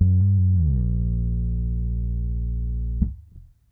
bass3.wav